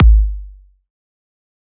EDM Kick 33.wav